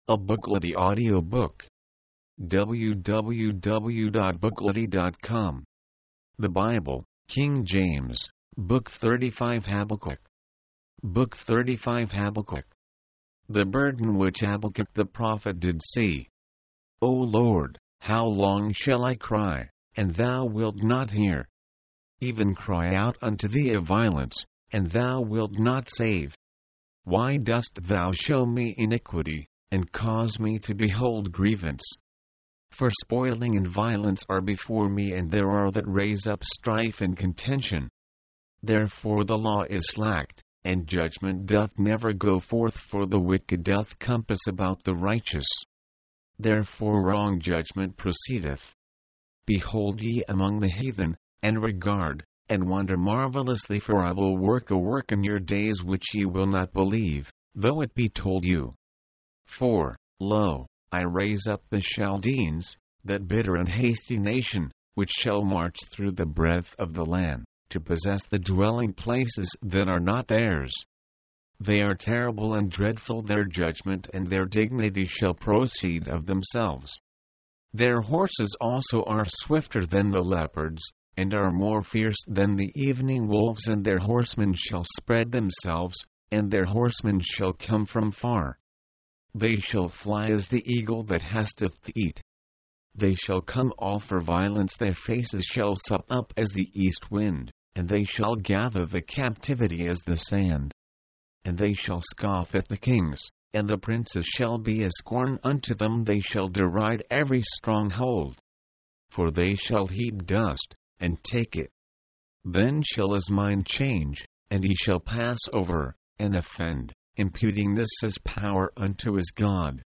Habakkuk Habakkuk's book features a dialogue between Habakkuk and God about suffering and justice. mp3, audiobook, audio, book Date Added: Dec/31/1969 Rating: Add your review